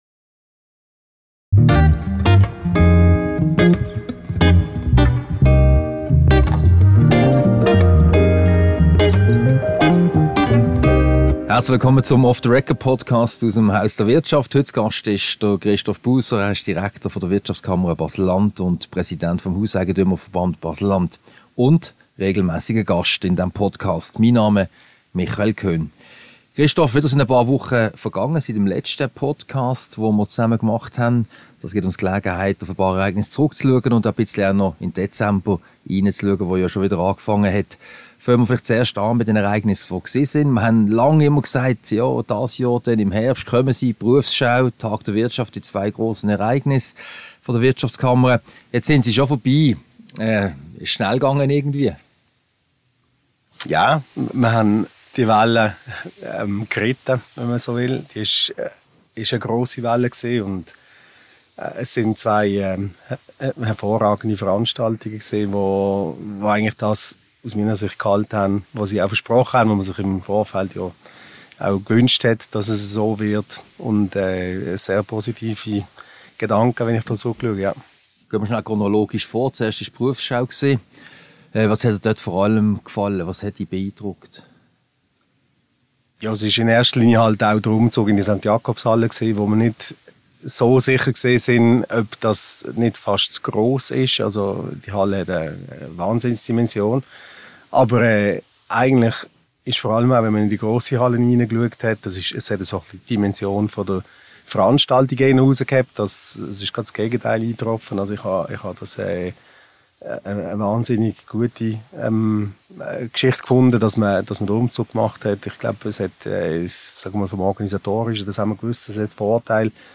Ein Gespräch über die beiden Grossveranstaltungen der Wirtschaftskammer Baselland: Die Berufsschau und den Tag Wirtschaft.